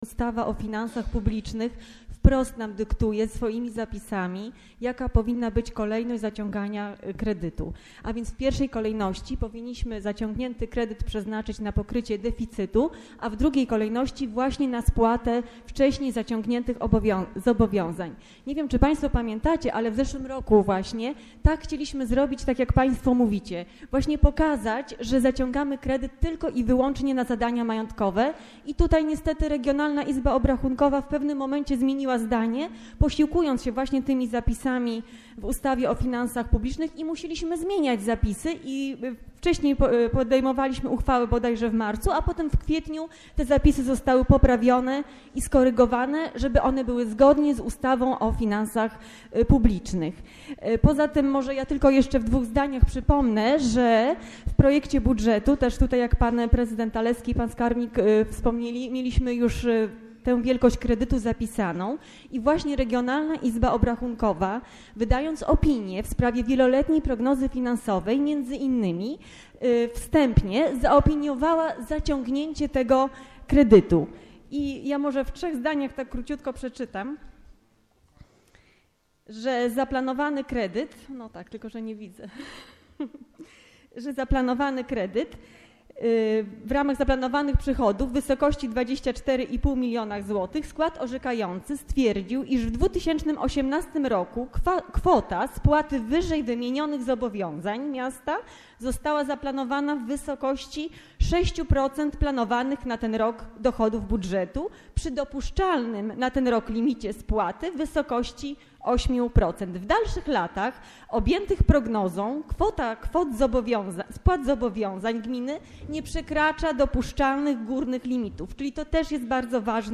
Bardzo zaskakujący przebieg miała wczorajsza sesja Rady Miasta.